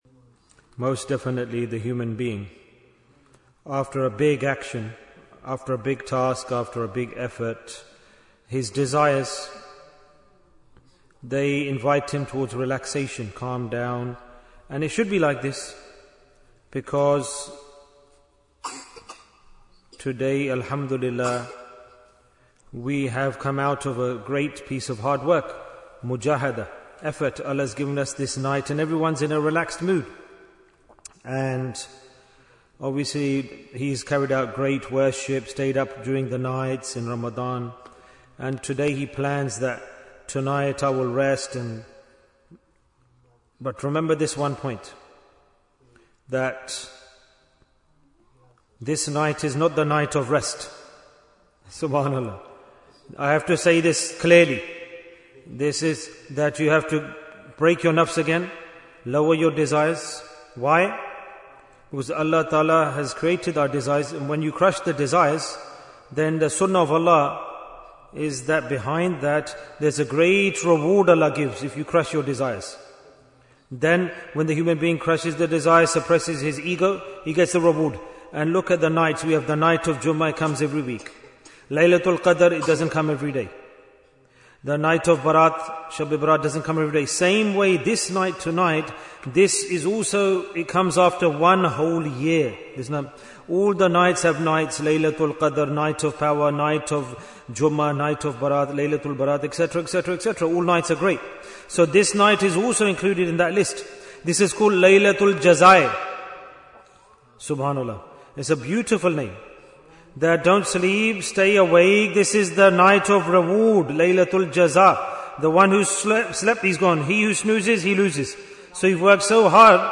The Night of Eid Bayan, 13 minutes29th March, 2025